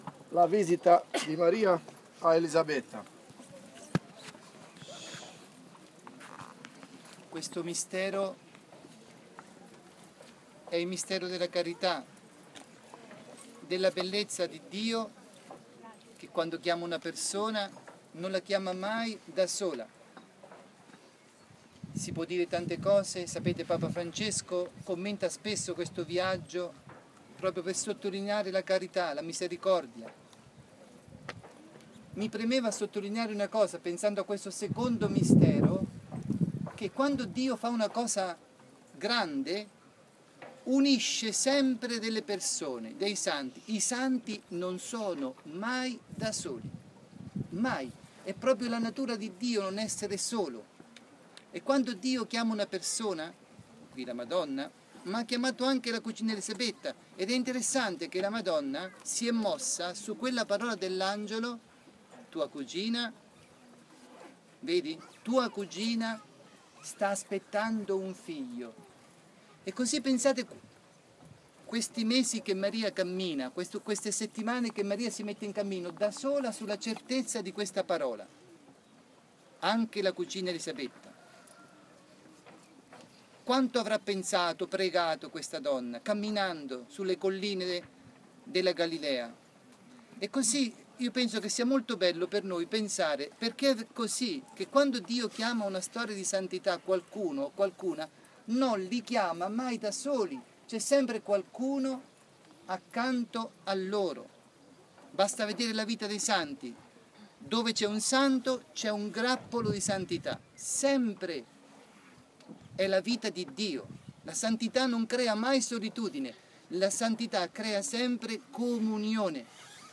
Pellegrinaggio a Medugorje del 15-20 maggio 2015
Sabato 16 maggio, ROSARIO  sulla collina delle apparizioni (Podbrdo)